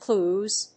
意味・対訳 クリューズ
/kluz(米国英語), klu:z(英国英語)/